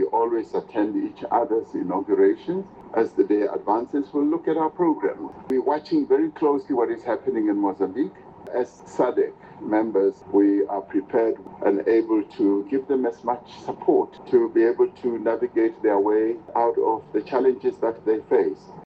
Ramaphosa het op die Progressiewe Sakeforum se Presidensiële Gholfdag in Kaapstad vreedsame dialoog tussen Chapo en die opposisieleier, Venâncio Mondlane, bepleit.